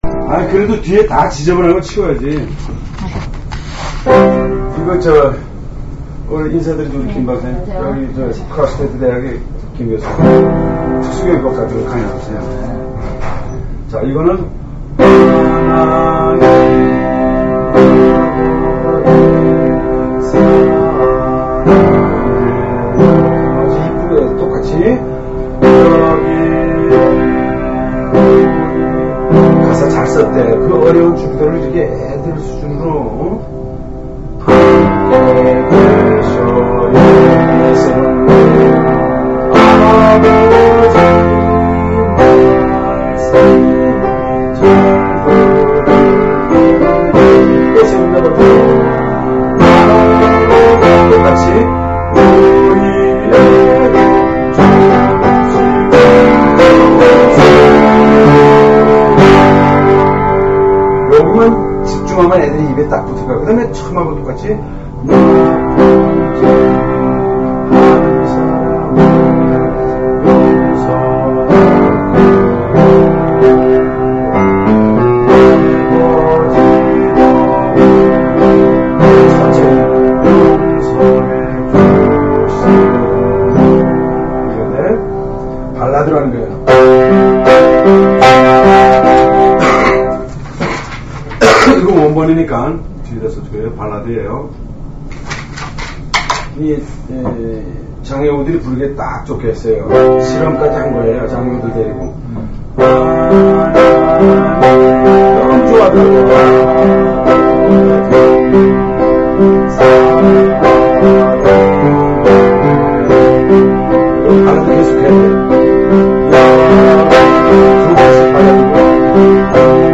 노래로 따라부르며 반복을 통해 쉽게 익힐 수 있어 지적장애인들도 예수님께서 가르쳐주신 기도로 하나님께 더 가까이 나갈 수 있기를 바라는 마음으로 음을 달아 노래로도 만들어 보았다